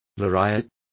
Complete with pronunciation of the translation of lariats.